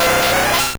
Cri de Tentacool dans Pokémon Or et Argent.